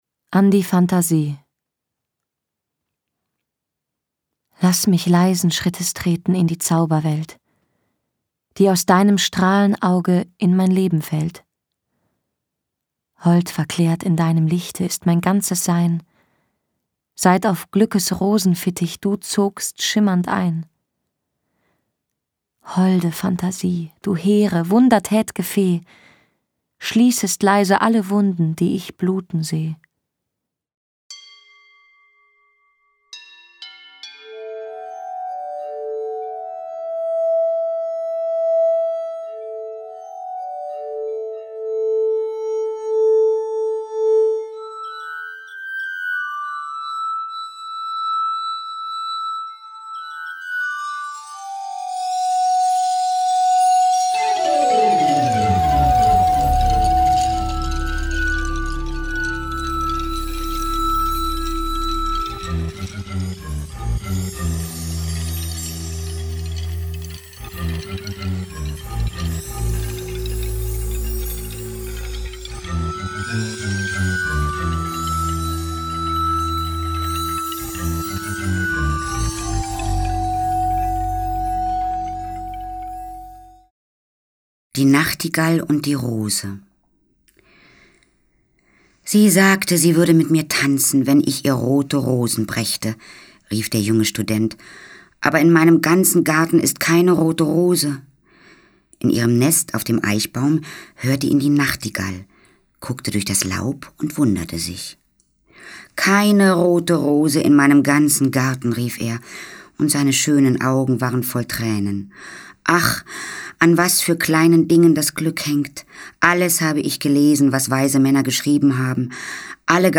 Kleine Geschichten, Gedichte und sanfte Melodien laden zum Genießen udn Träumen ein.
Schlagworte Gedichte • Geschichten • Lieder • Melodien